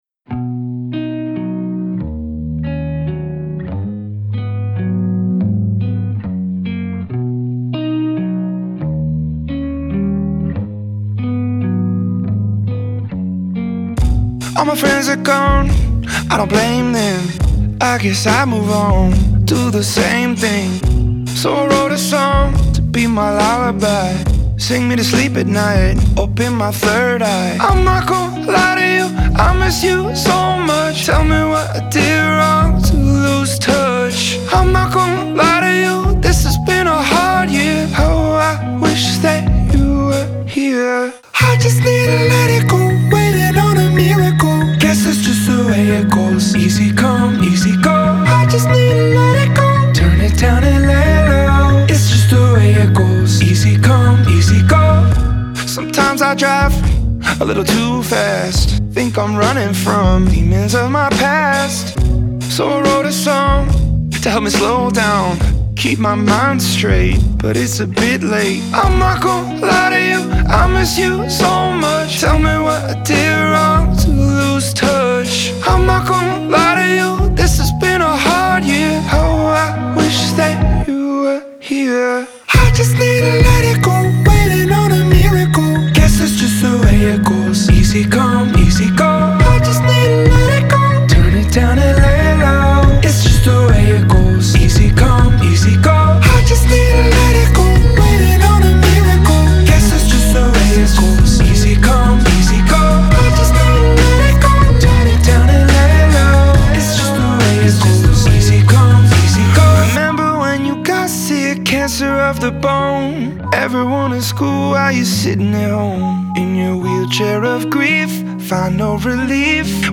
Genre : Alternative, Rock